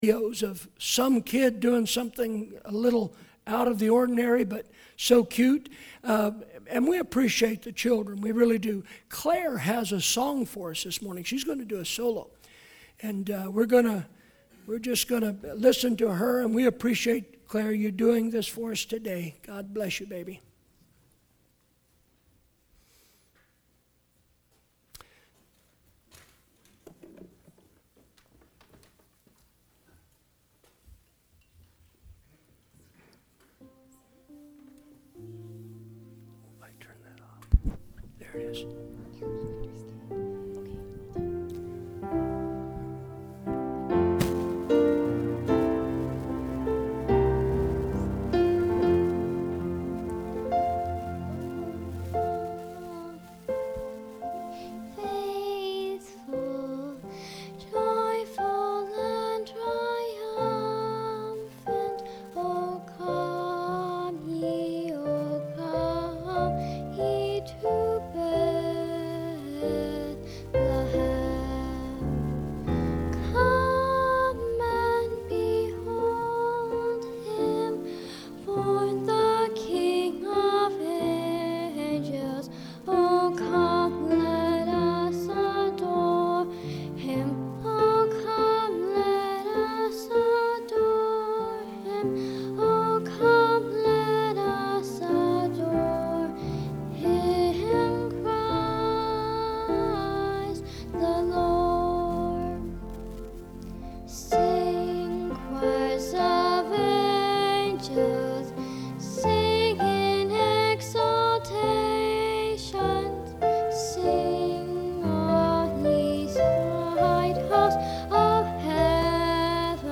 Song special